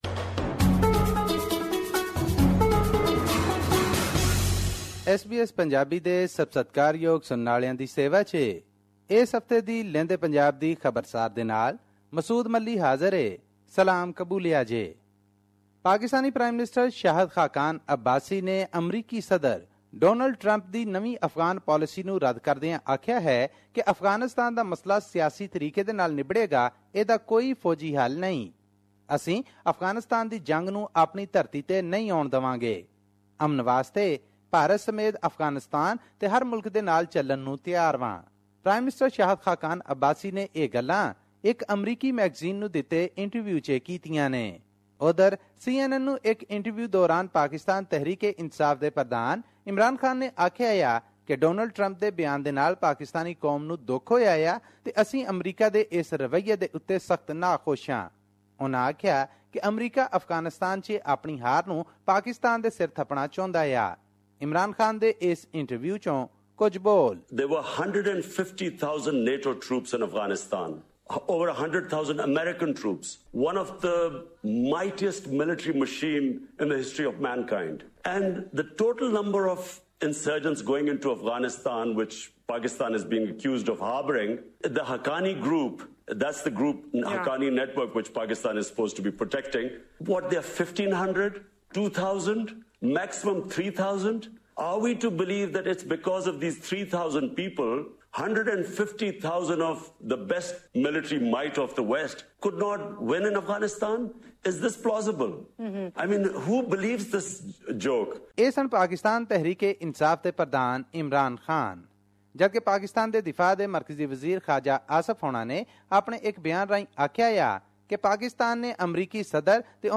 news update